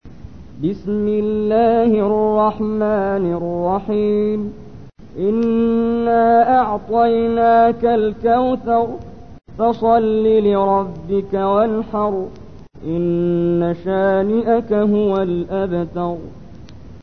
Audio icon ترتیل سوره کوثر با صدای محمد جبريل ازمصر (68.13 KB)